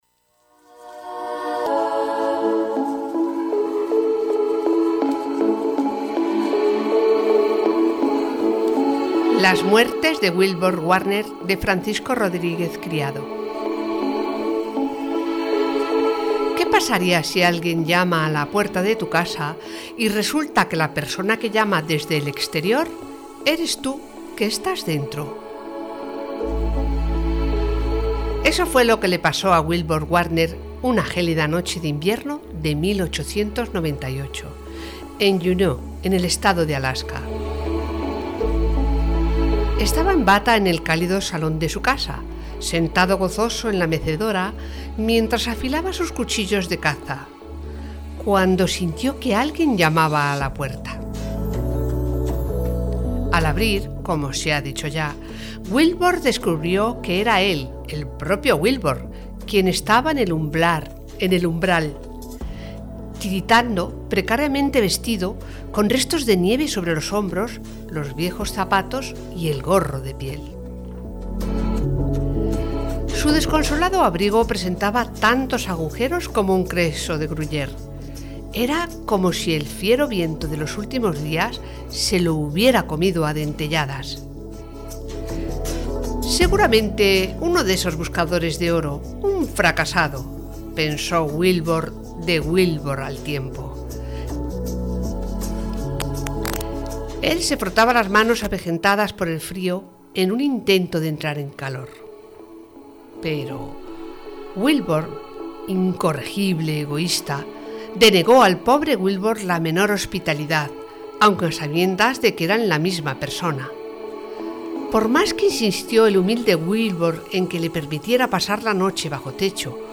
A través de su narración cercana y envolvente